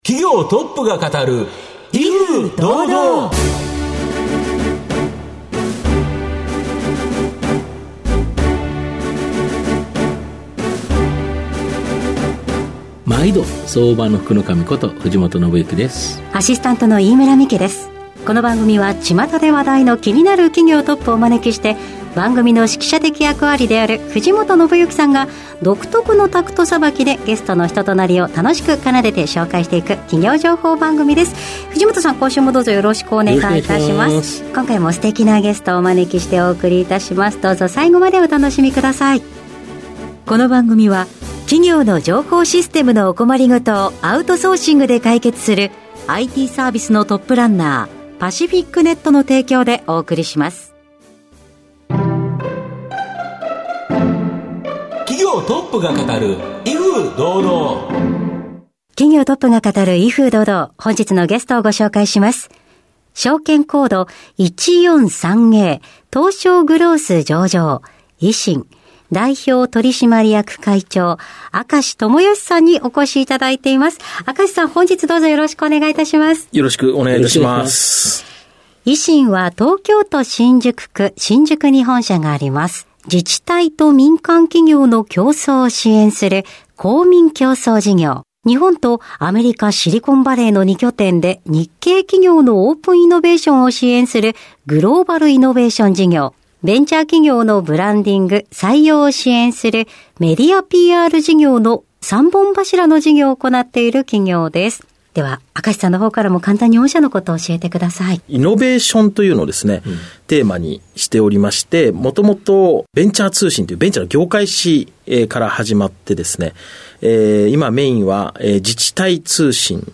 経営トップをゲストにお招きして事業展望や経営哲学などをうかがいつつ、トップの人となりにも迫るインタビュー番組です。番組の最後に毎回ゲストがピックアップする「四字熟語」にも注目！